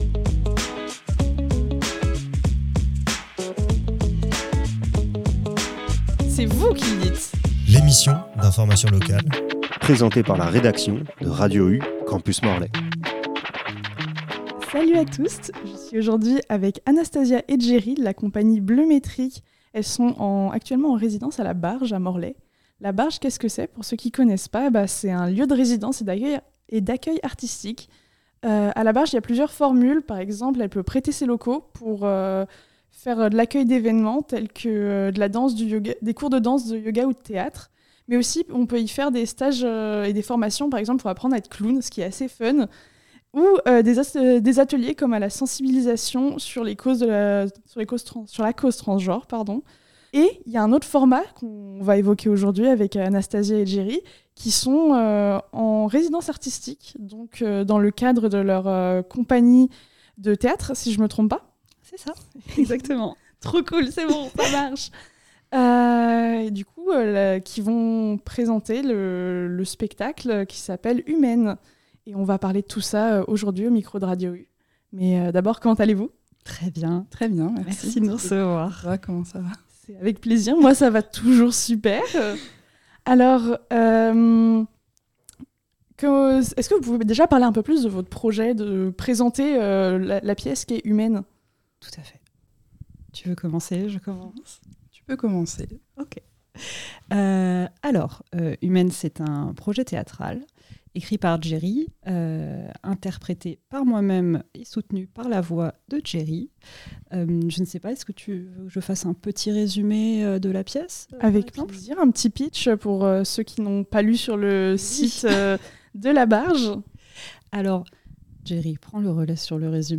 Elles sont au micro pour nous parler de la pièce "Humaine" qu’elles ont pu améliorer lors de cette résidence. Une odyssée de l’espace pas comme les autres, où l’on explore la complexité des émotions humaines dans un huis clos au fin fond de l’espace.